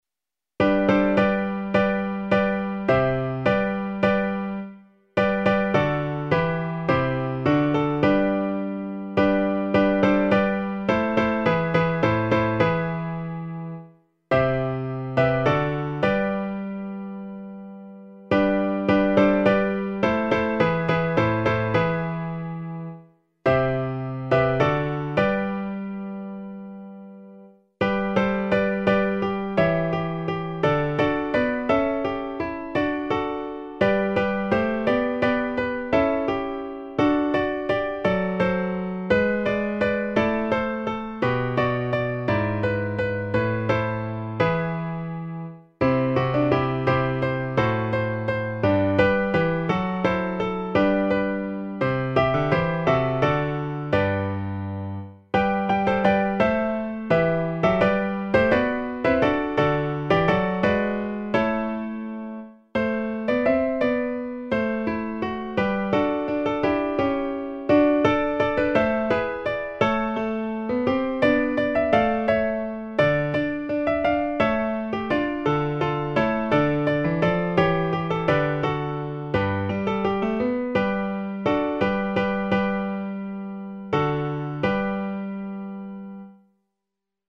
A 4 voces (Tiple I, II, Alto y Tenor)